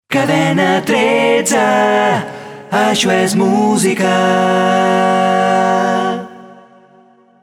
Indicatiu de la Cadena i de la radiofòrmula